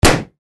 Звук взрыва рыбы фугу, как лопнувший шарик